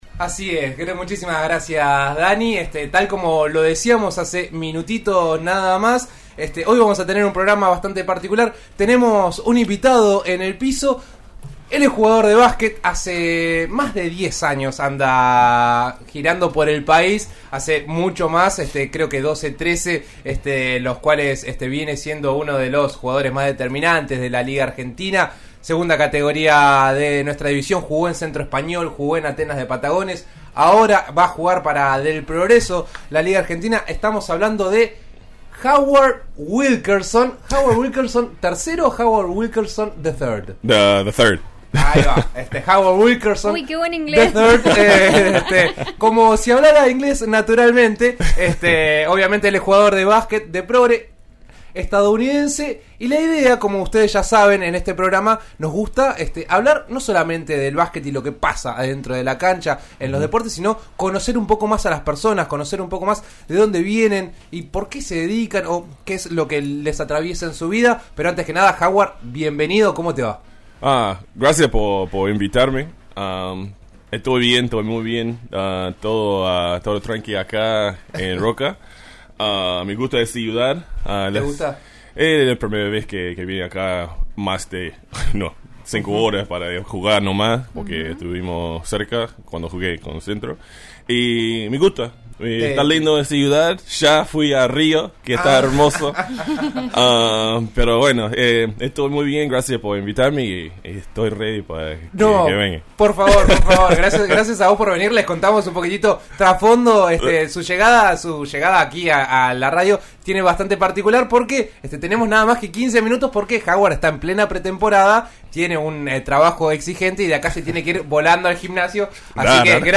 Éste jueves visitó a En eso estamos de RN RADIO 89.3 donde charló sobre su historia de vida, su llegada a Roca, las dificultades se intentar jugar en el extranjero y el racismo.